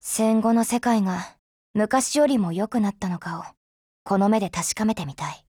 贡献 ） 协议：Copyright，其他分类： 分类:SCAR-H 、 分类:语音 您不可以覆盖此文件。